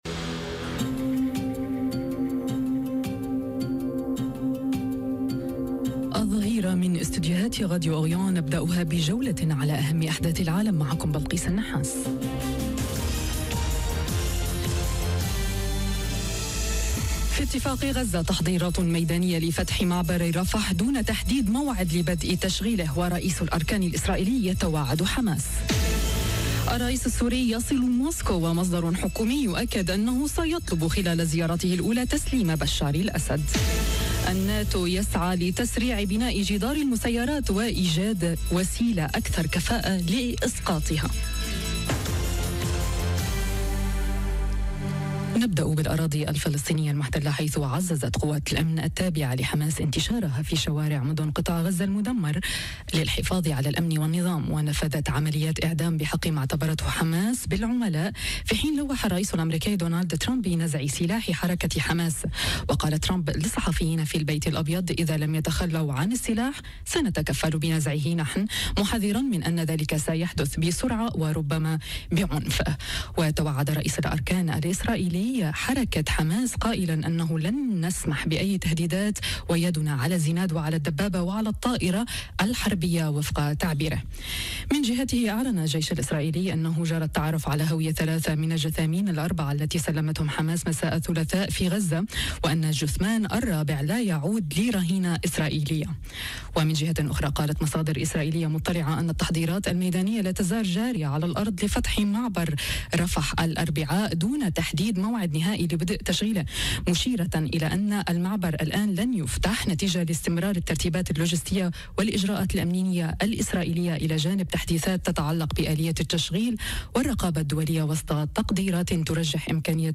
نشرة أخبار الظهيرة: تحضيرات ميدانية لفتح معبر رفح واتفاق غزة تحت الاختبار… توتر عسكري بين إسرائيل وحماس، وزيارة سورية مفصلية إلى موسكو، والناتو يعجّل بخطة "جدار المسيرات" - Radio ORIENT، إذاعة الشرق من باريس